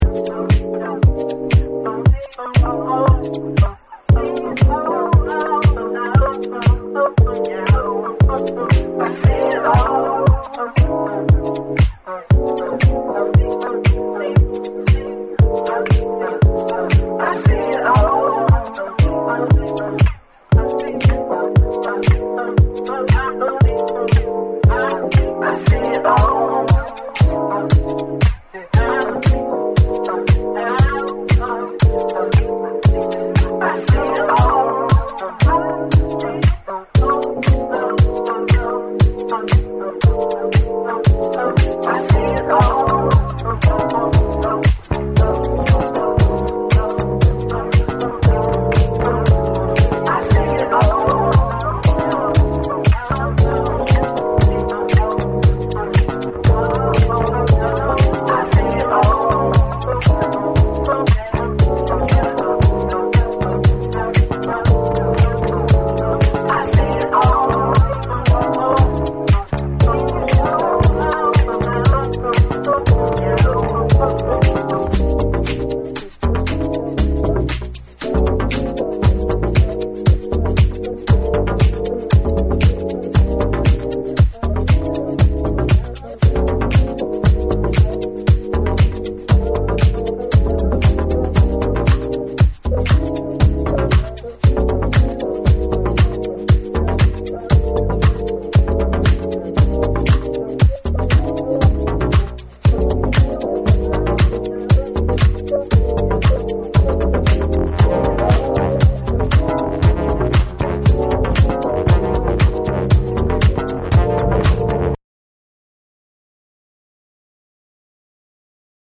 TECHNO & HOUSE / JAPANESE / NEW RELEASE